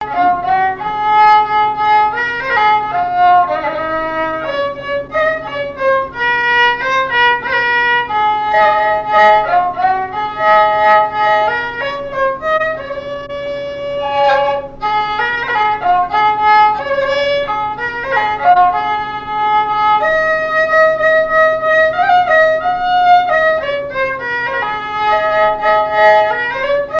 Beauvoir-sur-Mer
Répertoire de marches de noce et de danse
Pièce musicale inédite